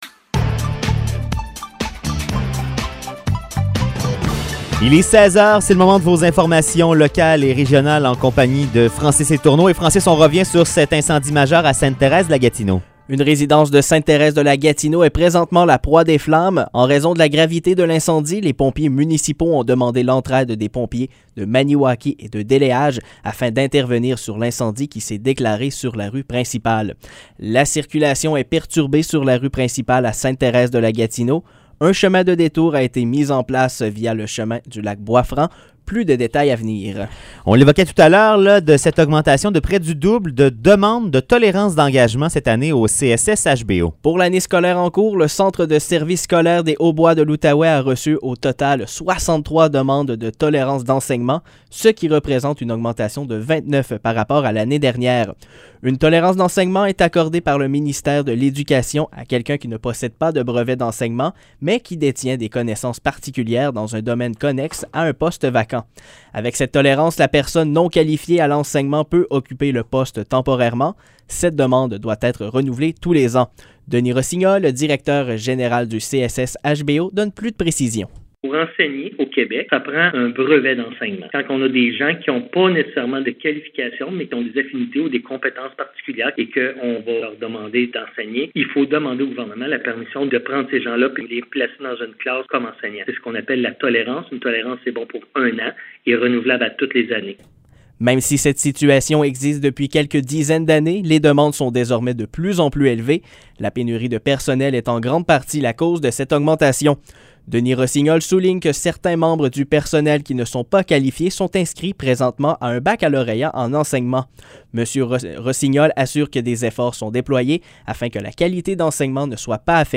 Nouvelles locales - 20 octobre 2021 - 16 h